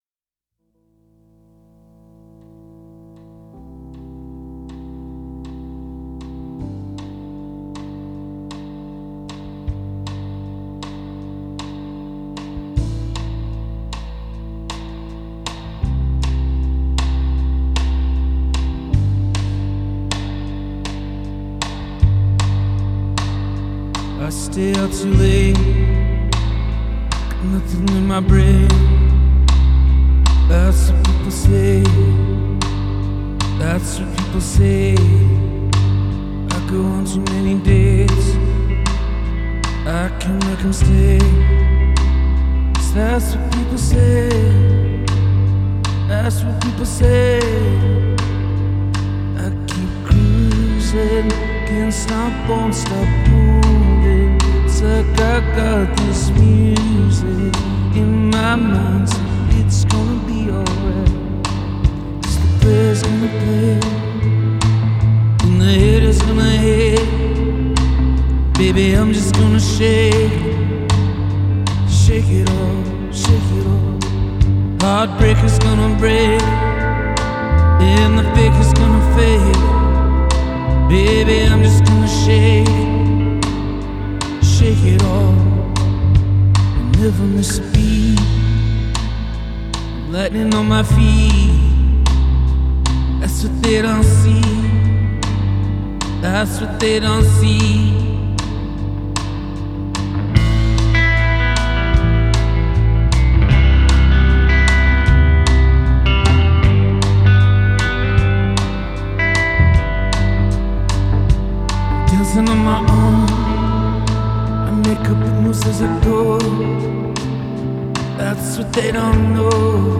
Genre: Rock, Folk, Pop, Covers